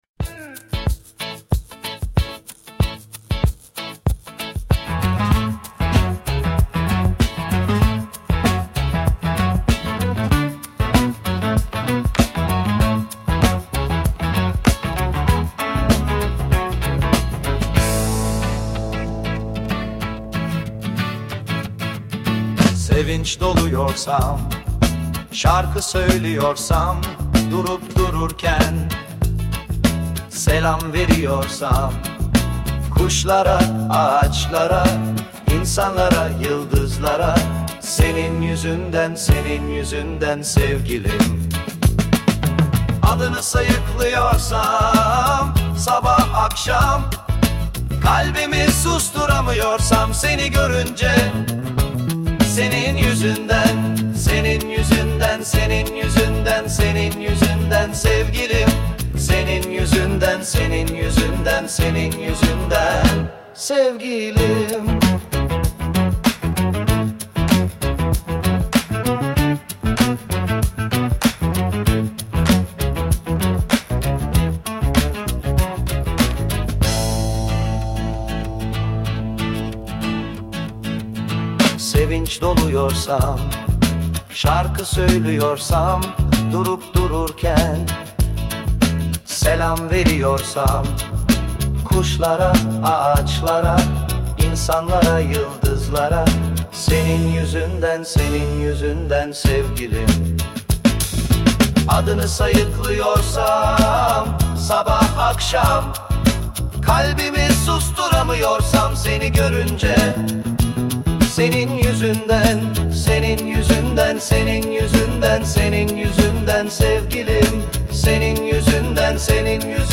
Tür : Pop, Rock